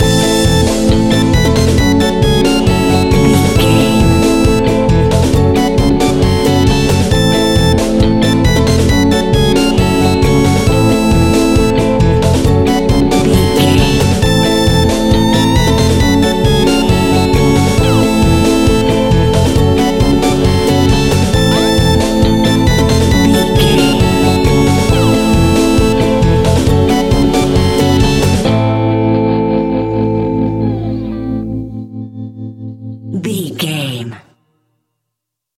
Aeolian/Minor
scary
ominous
eerie
piano
drums
bass guitar
synthesizer
horror music
Horror Synths